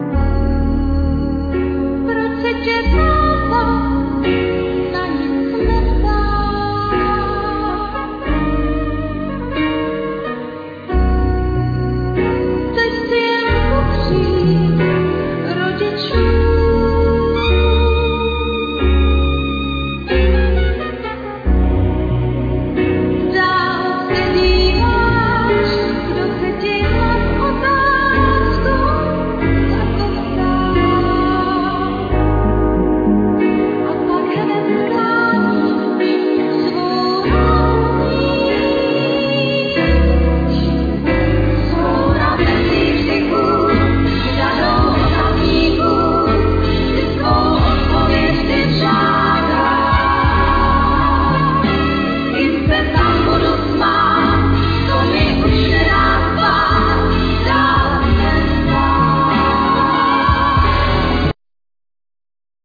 Keyboards, Fender piano
Sopranosax, Violin
Bass
Percussions
Drums
Vocal
Acoustic guitar
Guitar